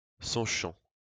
Sonchamp (French pronunciation: [sɔ̃ʃɑ̃]